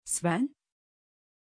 Aussprache von Sven
pronunciation-sven-tr.mp3